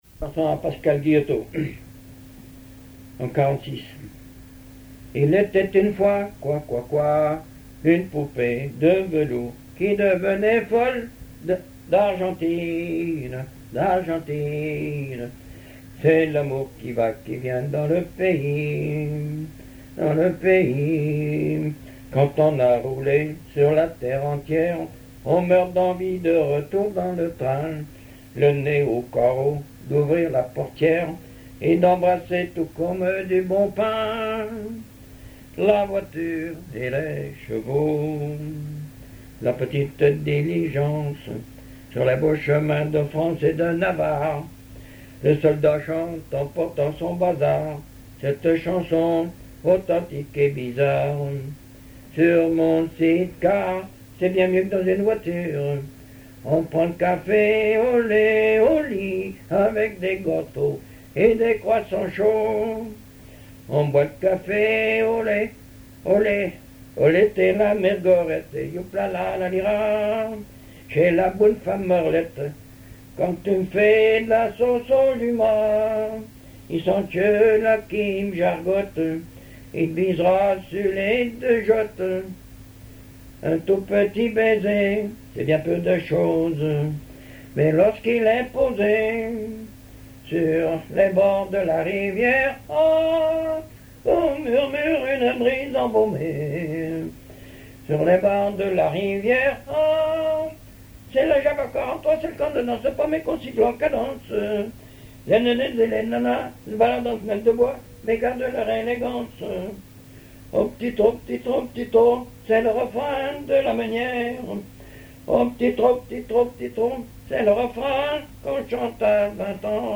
chansons populaires et histoires drôles
Pièce musicale inédite